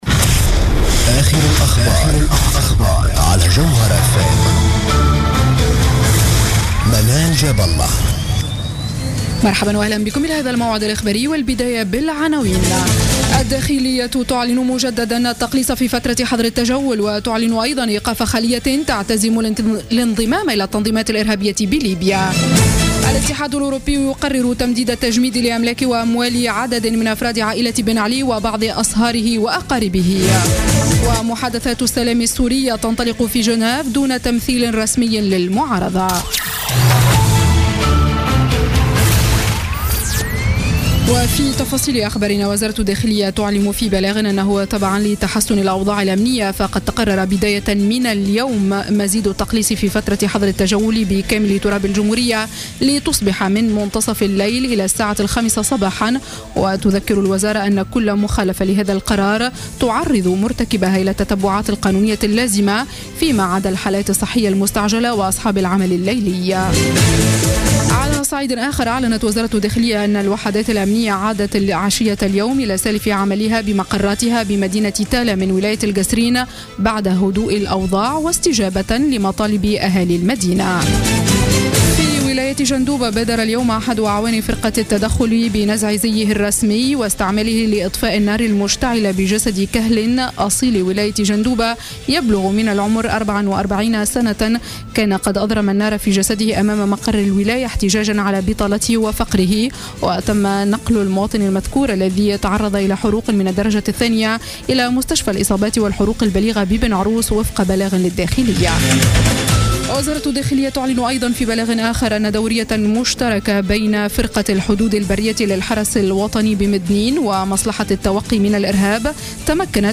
نشرة أخبار السابعة مساء ليوم الجمعة 29 جانفي 2016